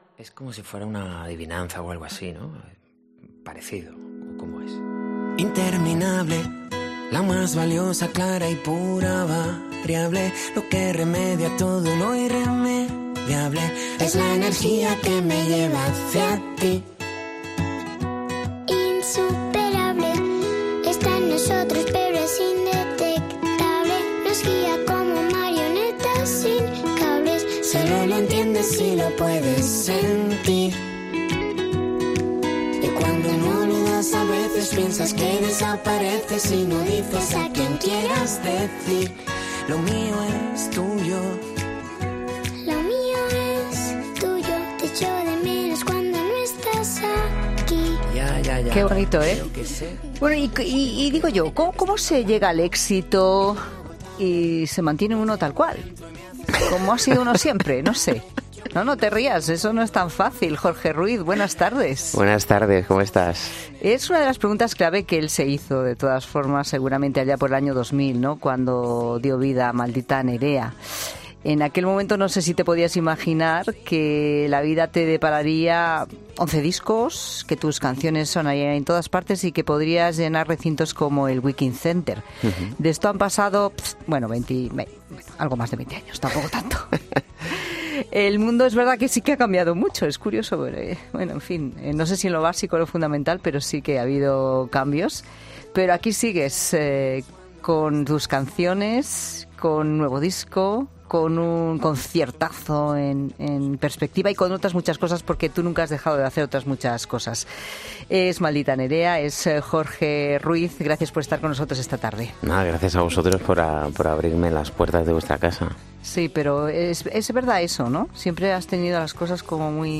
AUDIO: El vocalista de Maldita Nerea, Jorge Ruiz, ha estado en La Tarde para presentar su último trabajo 'Manual para seres maravillosos'.